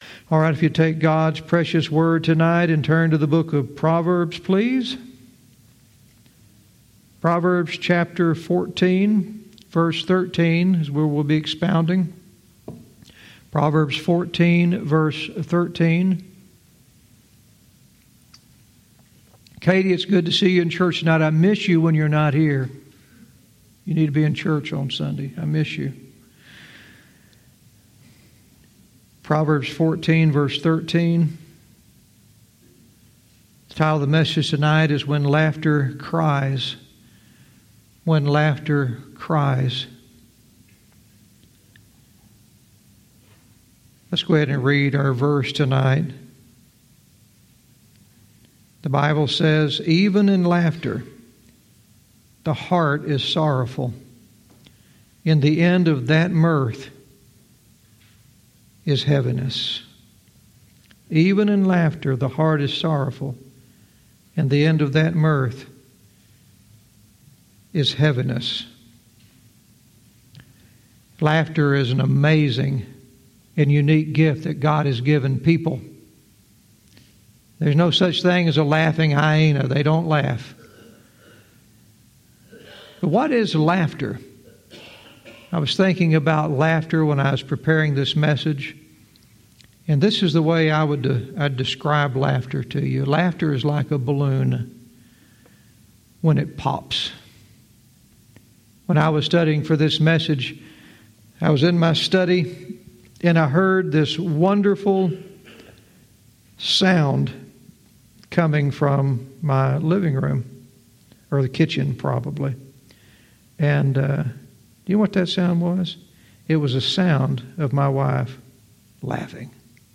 Verse by verse teaching - Proverbs 14:13 "When Laughter Cries"